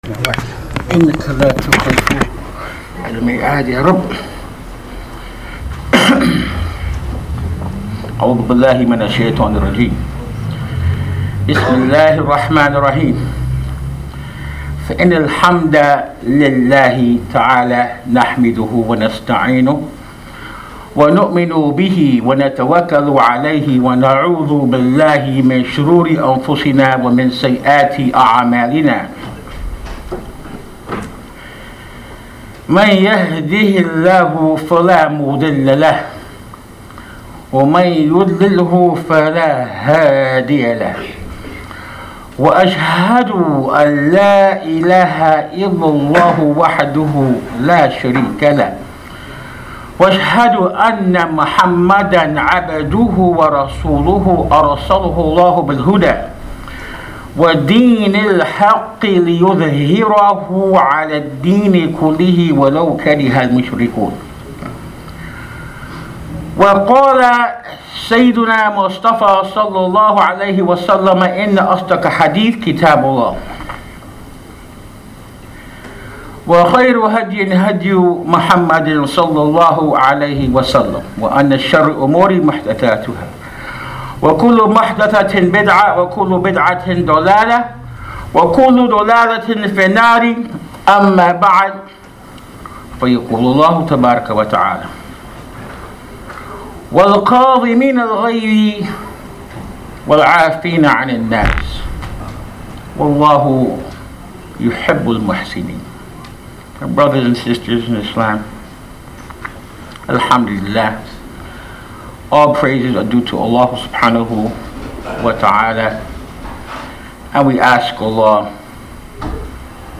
There is nothing that is simpler, more gratifying and more useful to the servant in this life and in the hereafter, than ikhlaas lillaah (sincerity to Allah). This is the topic of this khutba recorded at Masjid Ibrahim Islamic Center in Sacramento, California.